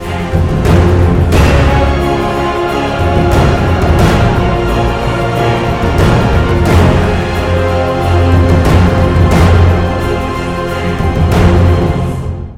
тревожные